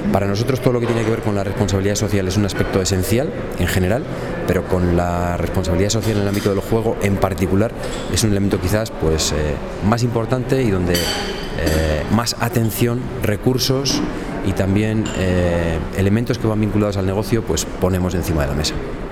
Una jornada de HISPACOOP sobre juego responsable en la sede de la ONCE repasa los retos que afronta este compromiso y acoge la presentación del último proyecto de investigación sobre esta materia galardonado por la Organización